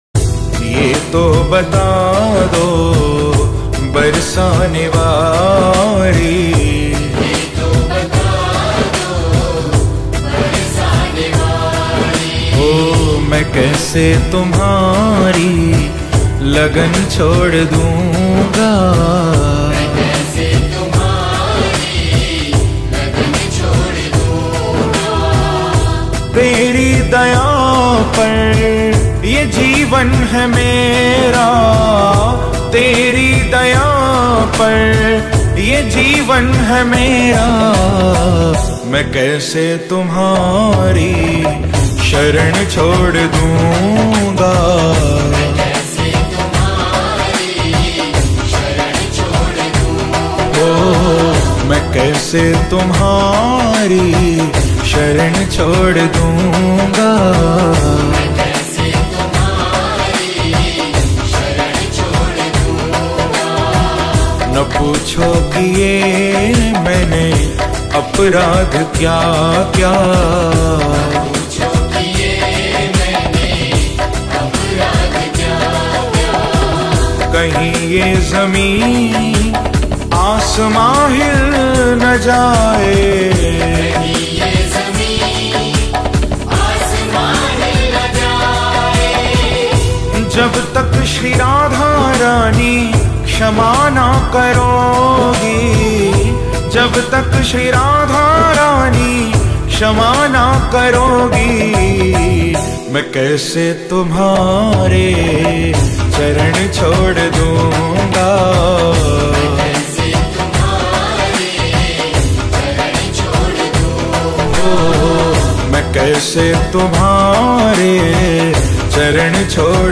Soulful Radha Bhajan